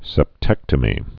(sĕp-tĕktə-mē)